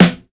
• Thick Snare Single Hit F Key 331.wav
Royality free snare sound tuned to the F note. Loudest frequency: 1602Hz
thick-snare-single-hit-f-key-331-aun.wav